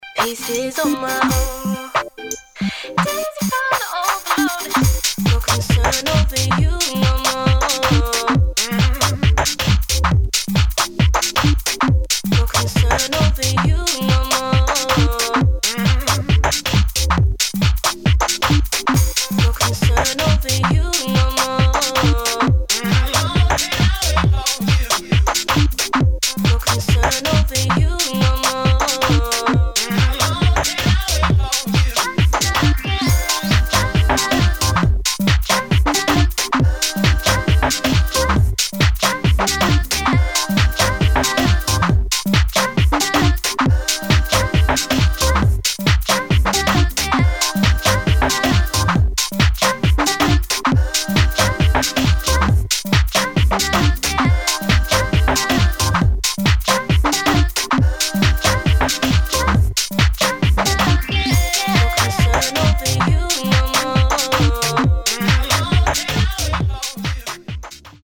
[ UK GARAGE ]